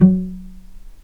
vc_pz-F#3-pp.AIF